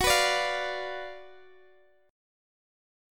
Listen to F#M7sus4#5 strummed